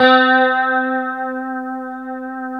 C#4 HSTRT MF.wav